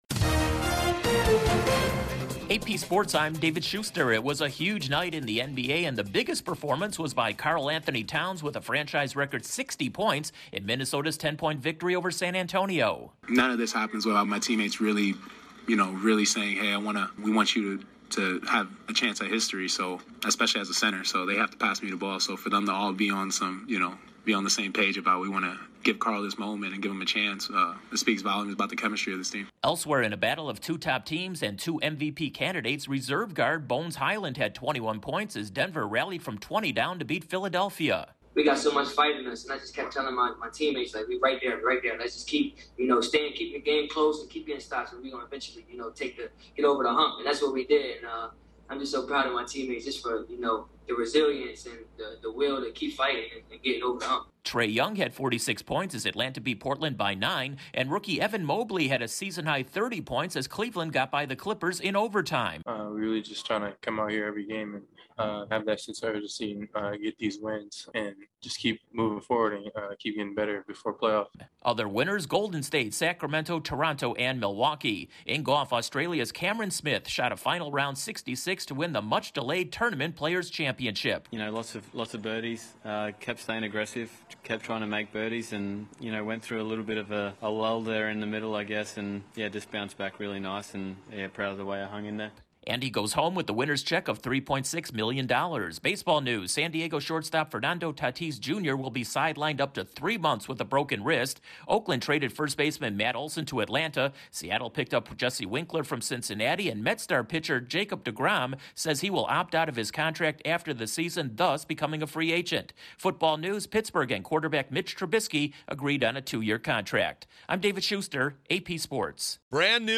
Towns powers the Timberwolves past the Spurs, Cameron Smith takes the Tournament Players Championship, and Mets' pitcher Jacob de Grom says he'll become a free agent. Correspondent